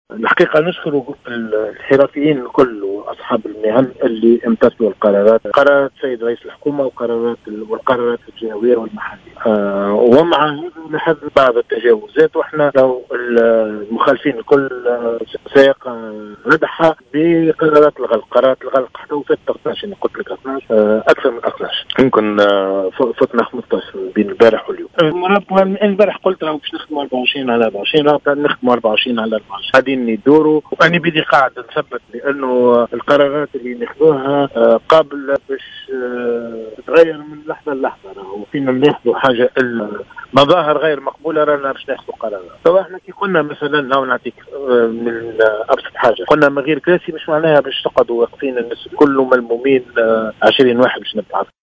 أكد رئيس بلدية سوسة محمد إقبال خالد، أنّه سيقع ردع المخالفين لقرارت رئيس الحكومة والسلطات المحلية، المتعلقة بالتوقي من فيروس كورونا، بقرارات الغلق.
و قال في تصريح للجوهرة أف أم، إن قرارات الغلق التي تمّ تنفيذها تجاوزت 15 قرارا، خلال 48 ساعة الماضية، مشددا على تطبيق القانون بصرامة، خاصة فيما يتعلّق بقرار منع الكراسي و الطاولات في المقاهي، معبرا عن استغرابه من تواصل تجمع المواطنين أمام المقاهي، و عدم اكتراثهم لخطورة ذلك، رغم التوصيات و المجهودات المبذولة، للتوقي من تفشي فيروس كورونا.